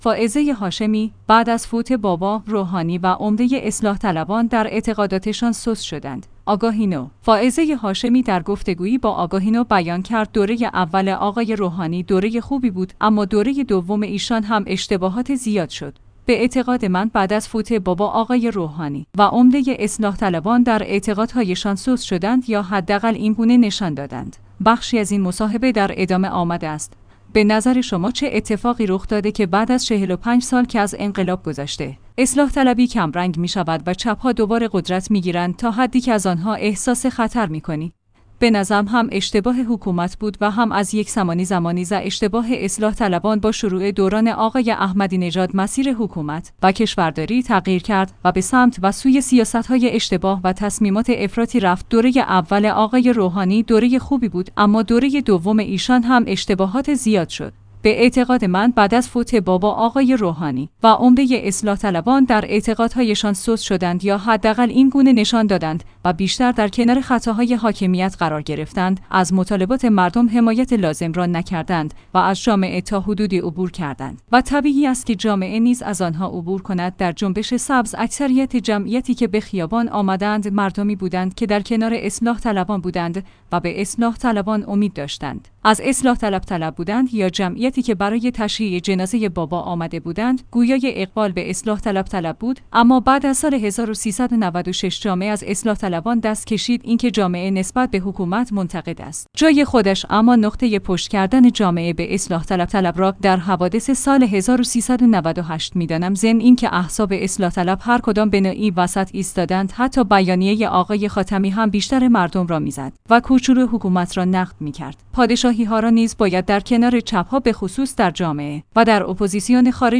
آگاهی نو/ فائزه هاشمی در گفتگویی با آگاهی نو بیان کرد دوره اول آقای روحانی دوره خوبی بود اما دوره دوم ایشان هم اشتباهات زیاد شد. به اعتقاد من بعد از فوت بابا آقای روحانی و عمده اصلاح طلبان در اعتقادهایشان سست شدند یا حداقل این گونه نشان دادند.